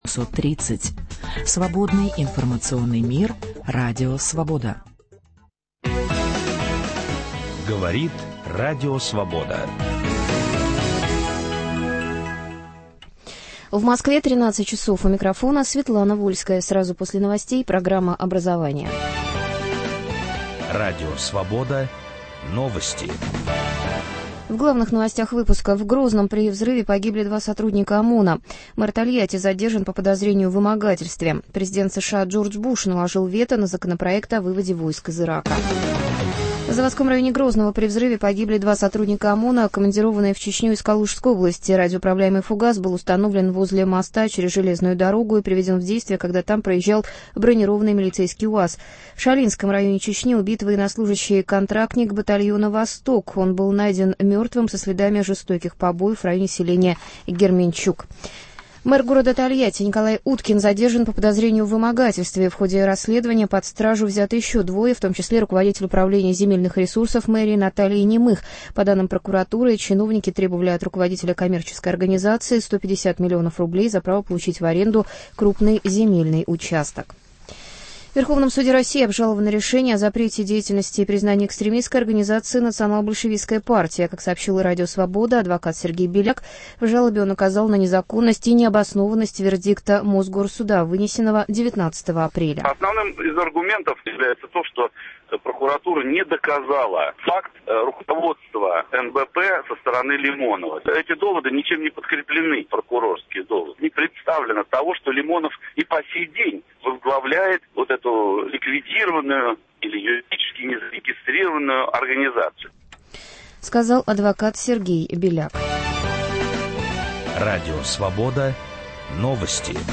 Летние образовательные лагеря и экспедиции со школьниками. В студии радио "Свобода"